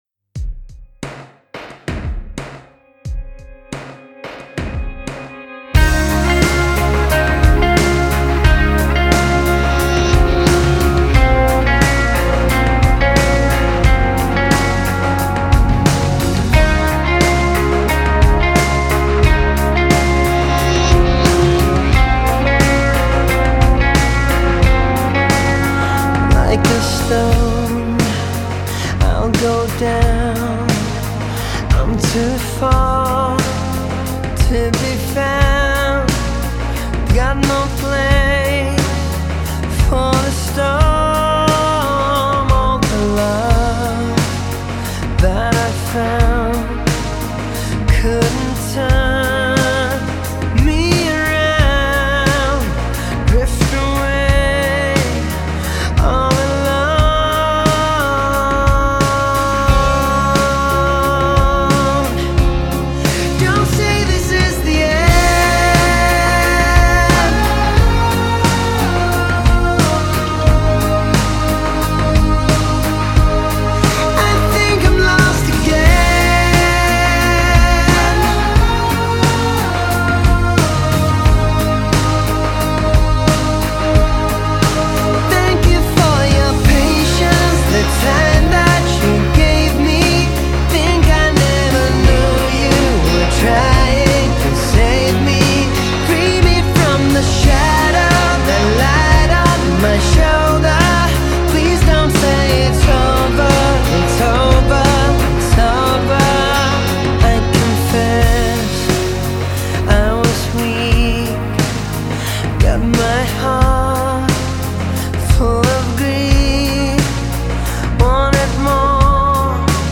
Finland Жанр: Alternative Rock